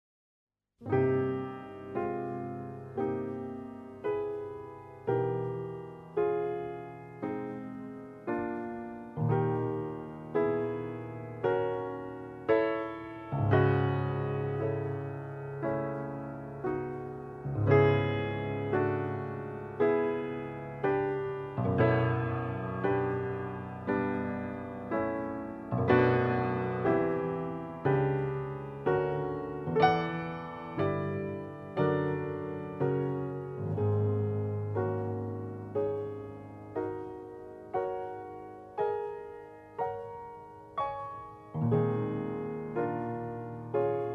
newly remastered